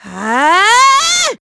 Ripine-Vox_Casting2.wav